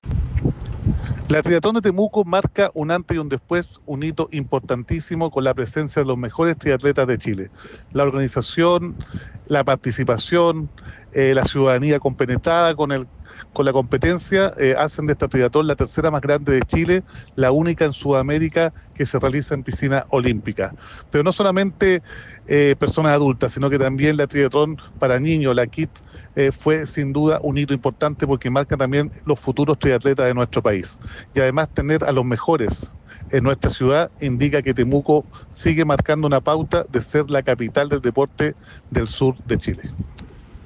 El alcalde de Temuco, Roberto Neira Aburto, valoró el impacto de la competencia en la ciudad: «La triatlón de Temuco marca un antes y un después, un hito importantísimo con la presencia de los mejores triatletas de Chile.
Roberto-Neira-alcalde-Temuco.mp3